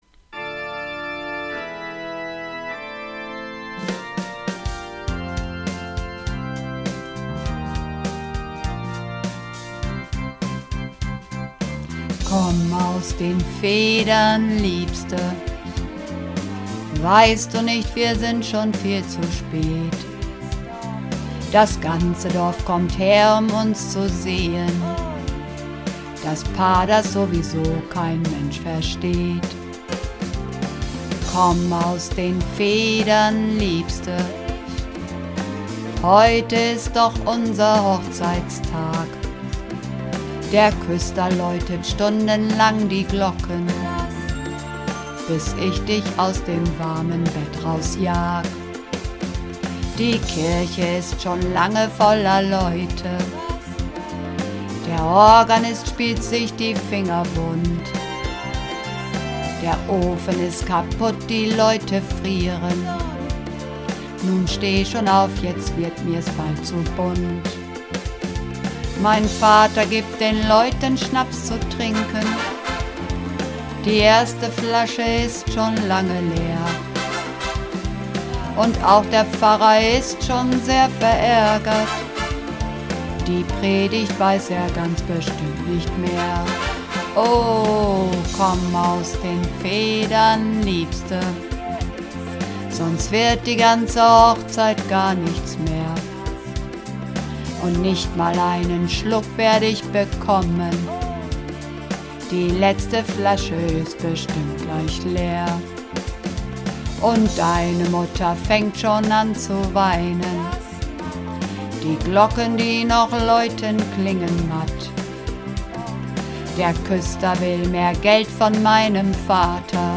Übungsaufnahmen - Komm aus den Federn, Liebste!
Komm_aus_den_Federn_Liebste__2_Bass.mp3